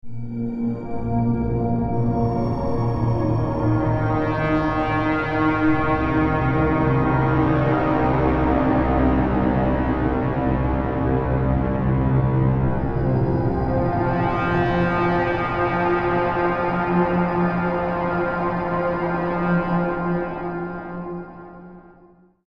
标签： 请求 音乐 新闻 新闻大开眼界 大开眼界 广播节目 显示大开眼界 介绍 电视
声道立体声